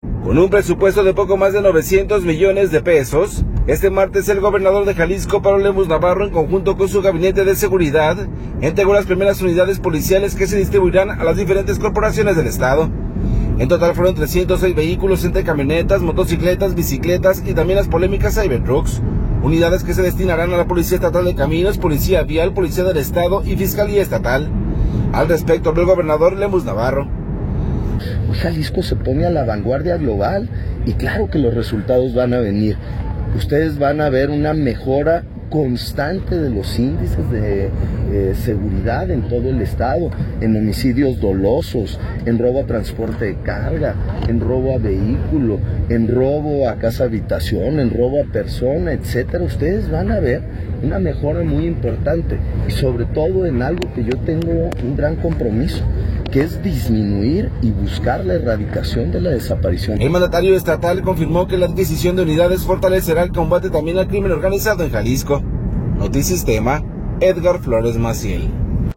Al respecto habló el gobernador Pablo Lemus.